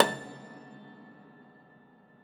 53o-pno17-A3.wav